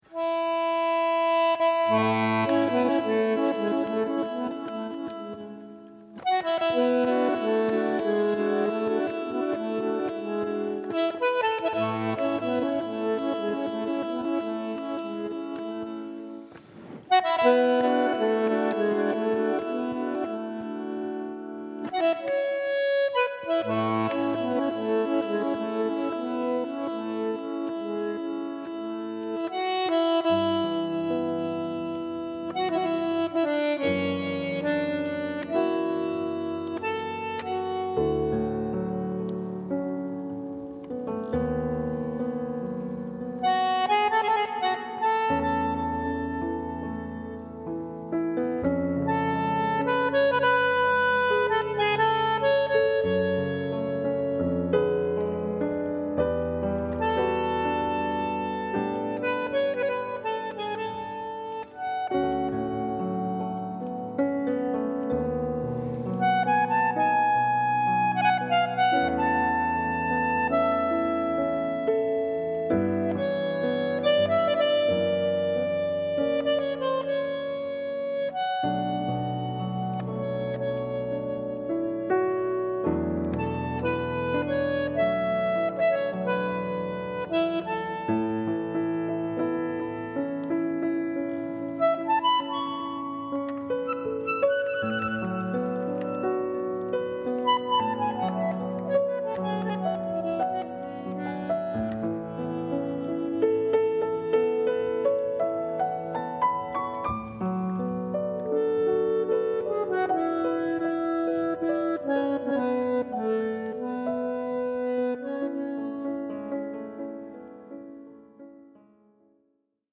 in equilibrio tra scrittura ed improvvisazione